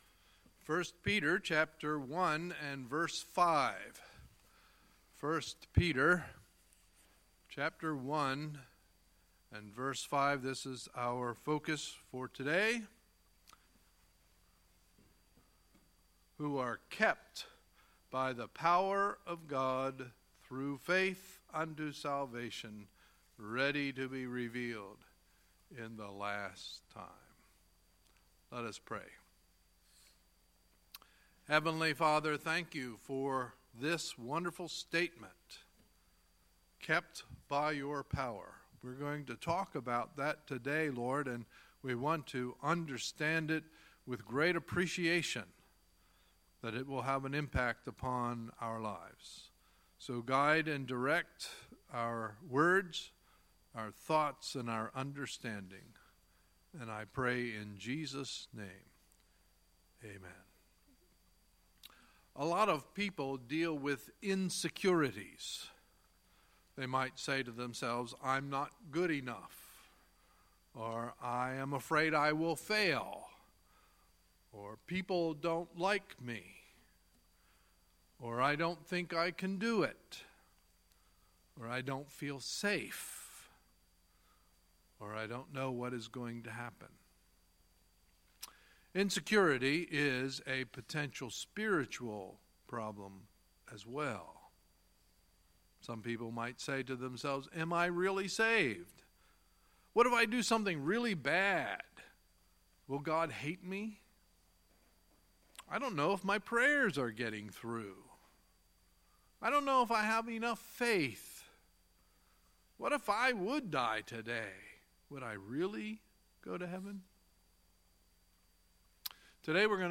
Sunday, October 1, 2017 – Sunday Morning Service